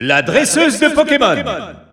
Announcer pronouncing male Pokémon Trainer in French in victory screen.
Pokémon_Trainer_F_French_Alt_Announcer_SSBU.wav